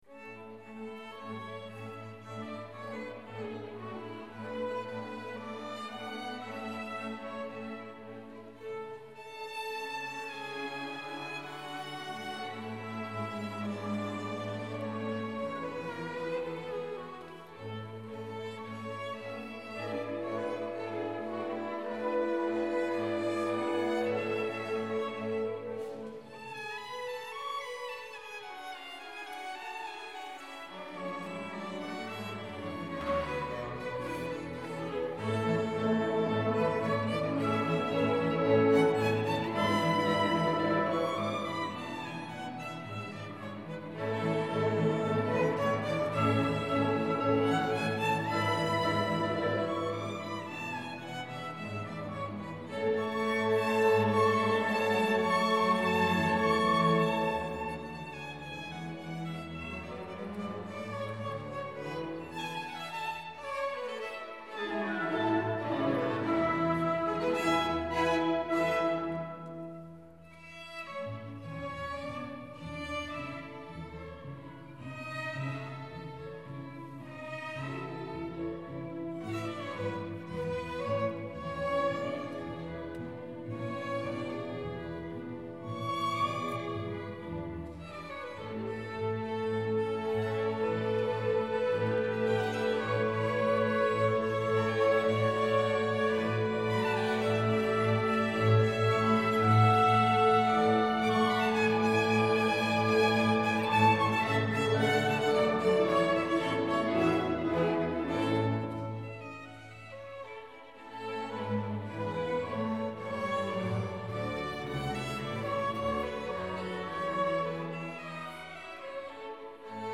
2019-01-26 Hindersmässokonsert S:t Nicolai kyrka i Örebro tillsammans med Kumla Hallsbergs orkesterförening
Inspelningen är gjord med en Zoom H4n Pro (24-bit/48KHz sampling)
6_Piano_konsert.mp3